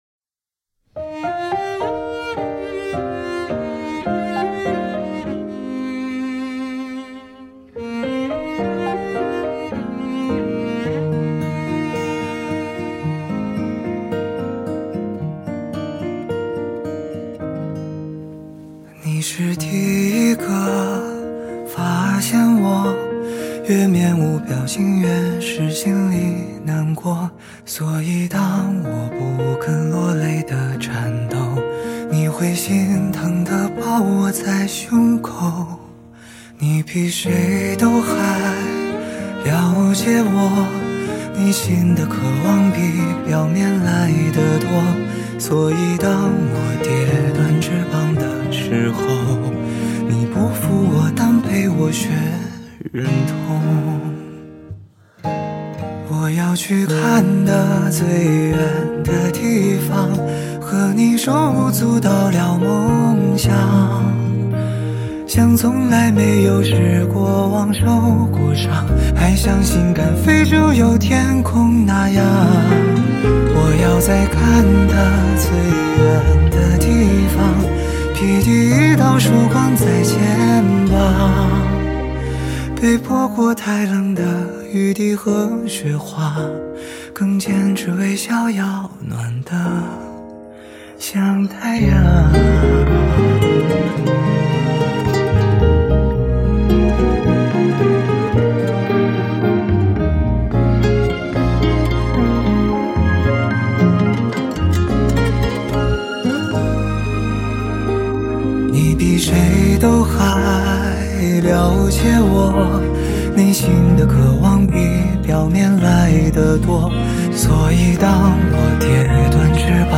4/4 60以下
华语